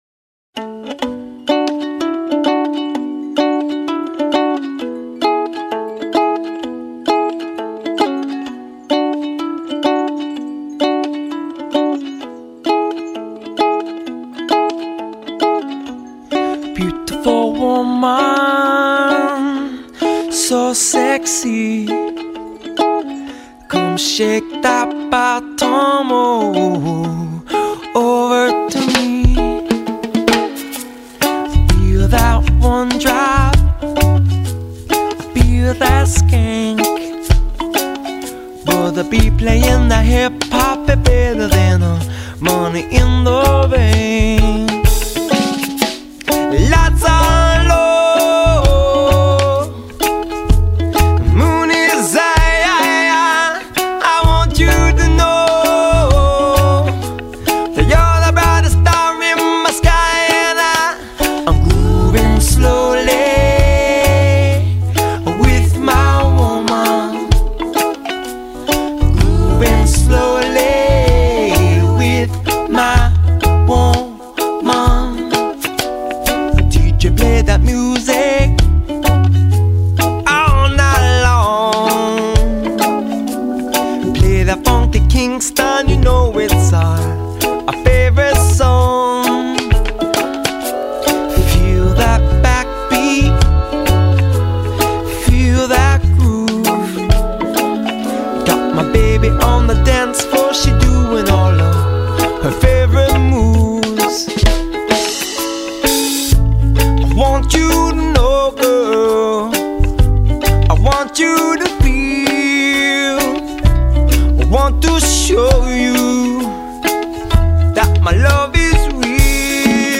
you guessed it, ukulele driven sexy jam.